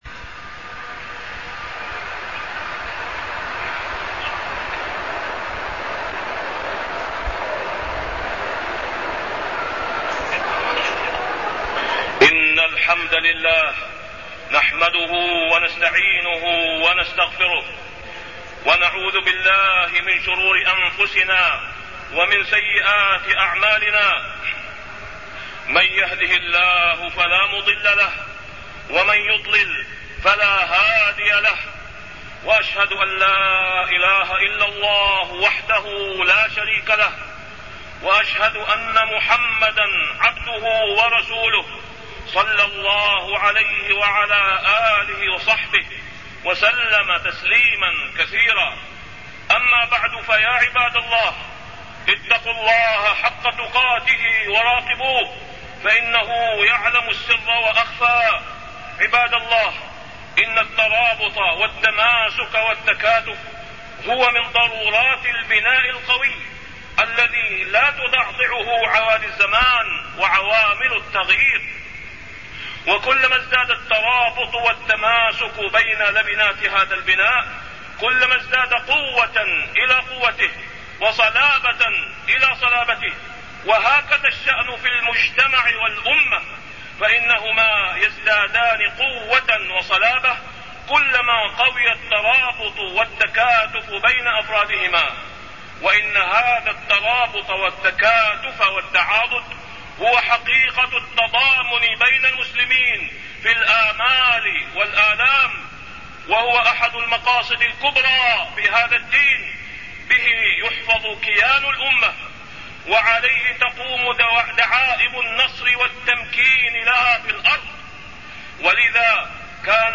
تاريخ النشر ٢٥ جمادى الأولى ١٤٢١ هـ المكان: المسجد الحرام الشيخ: فضيلة الشيخ د. أسامة بن عبدالله خياط فضيلة الشيخ د. أسامة بن عبدالله خياط التضامن الإسلامي